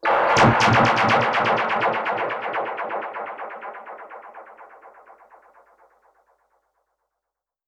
Index of /musicradar/dub-percussion-samples/125bpm
DPFX_PercHit_B_125-04.wav